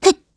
Gremory-Vox_Attack1_kr.wav